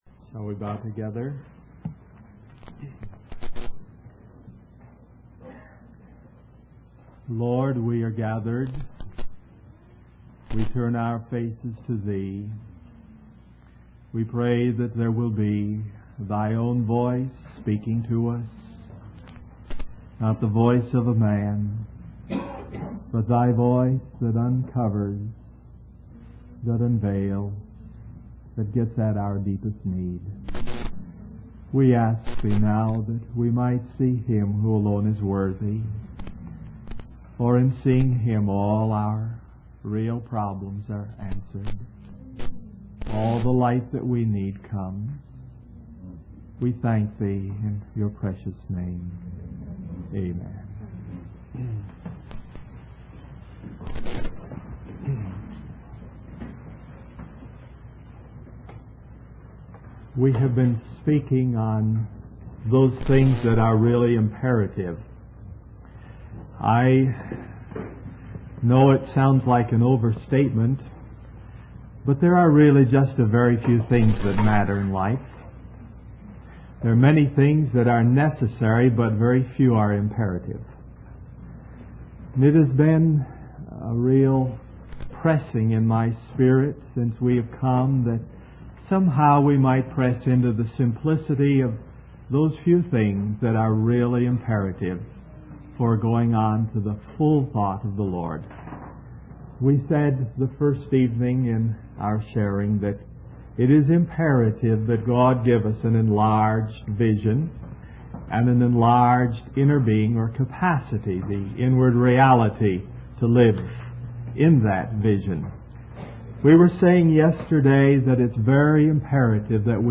In this sermon, the speaker discusses the need for a proper vessel or vehicle to contain and express the life that God is pouring forth. He uses the analogy of the Hoover Dam to illustrate this concept. The speaker emphasizes that trying to interpret the world and religious situations through natural reasoning will only lead to limited understanding.